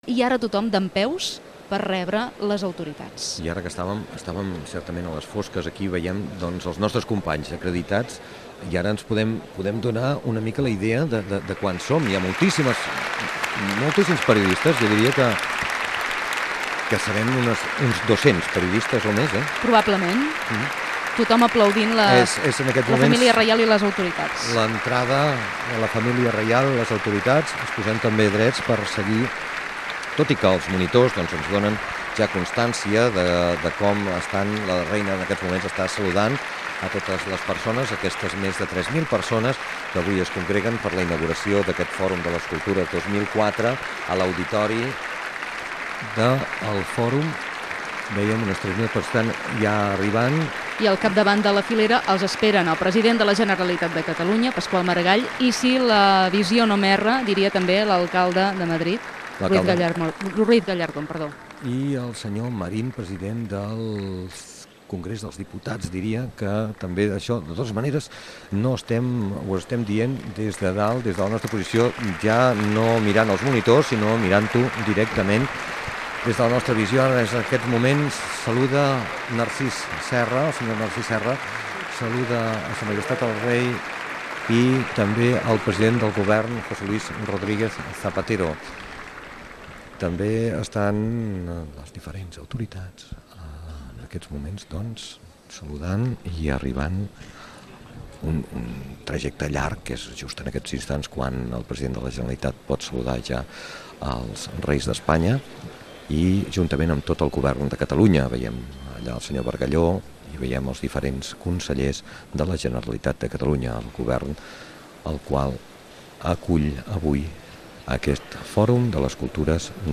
Fragment de la transmissió de la inauguració del Fòrum Mundial de les Cultures 2004. Entrada d'autoritats i el Rei Juan Carlos I dona pas a l'alcalde de Sant Adrià del Besòs, Jesús María Canga
Informatiu